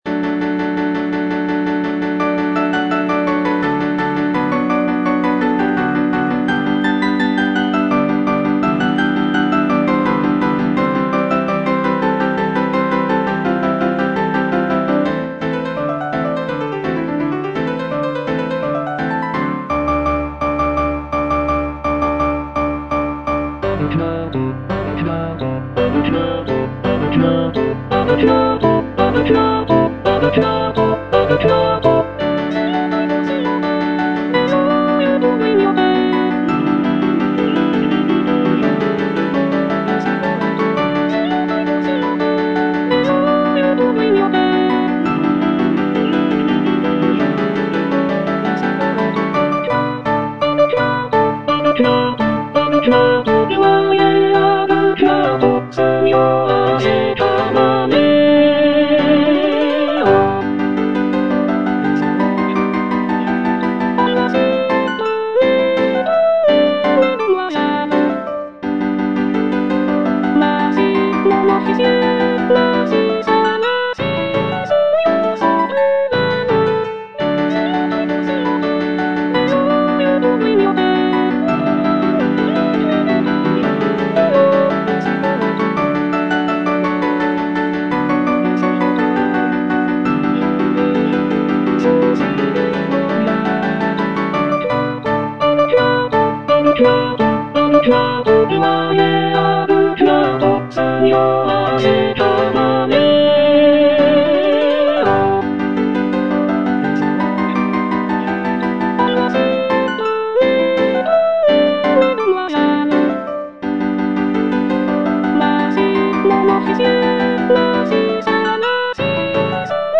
G. BIZET - CHOIRS FROM "CARMEN" A deux cuartos (soprano II) (Emphasised voice and other voices) Ads stop: auto-stop